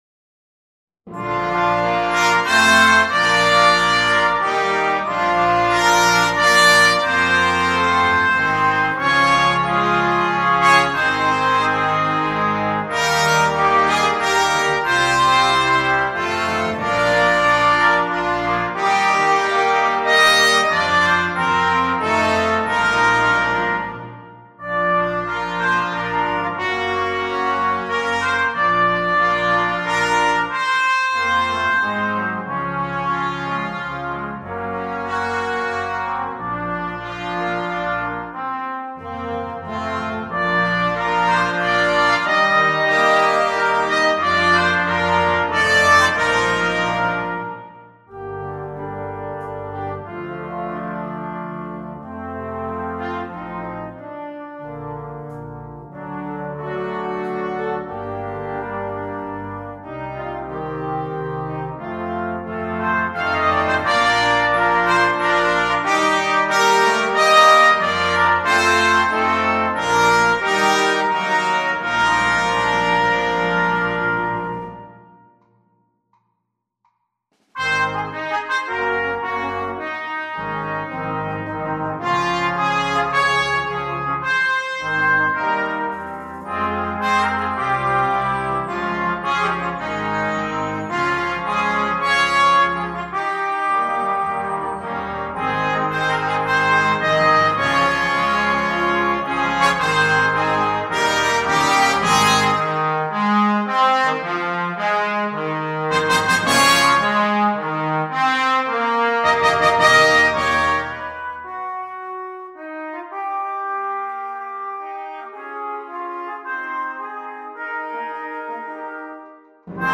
2. Orquesta de viento juvenil (flex)
8 Partes y Percusión
sin un solo instrumento
Música ligera
Percusión